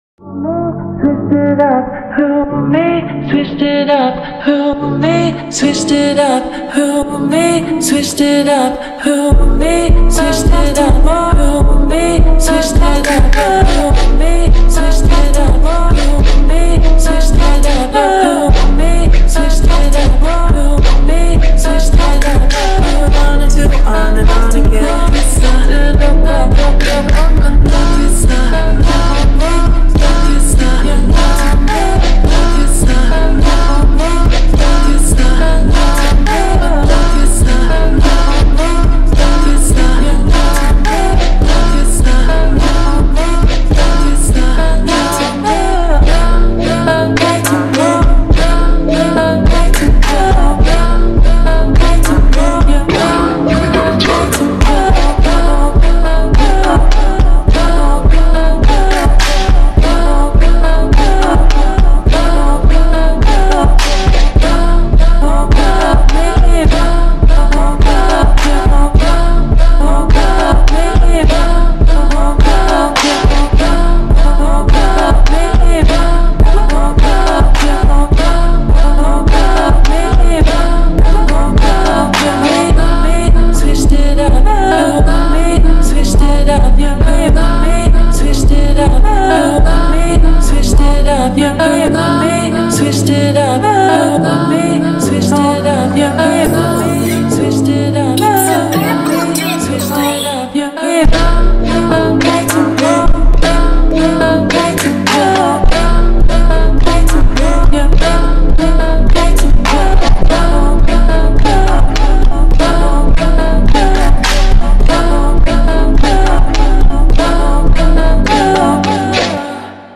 فانک
ماشینی